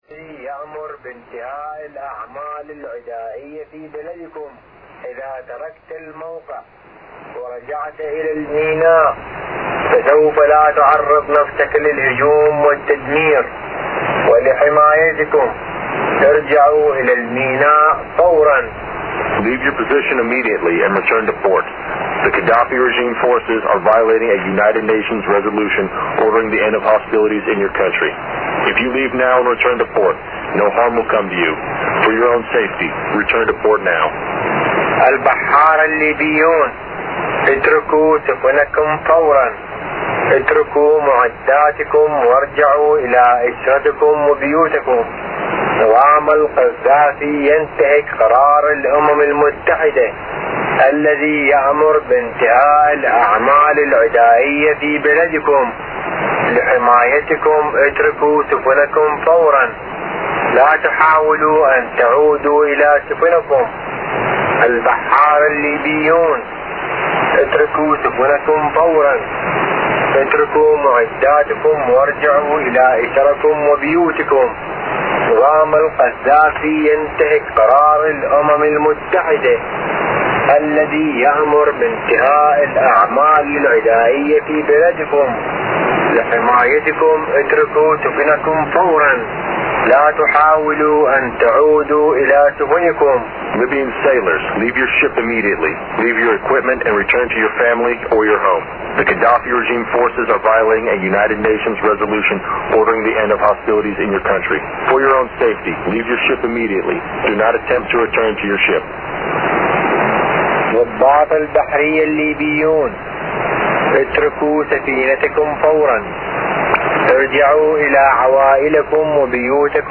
Начало » Записи » Записи радиопереговоров - NATO и союзники
Вещание, предположительно, ведется с борта EC-130.
Частота 10405 kHz USB, T=1446z.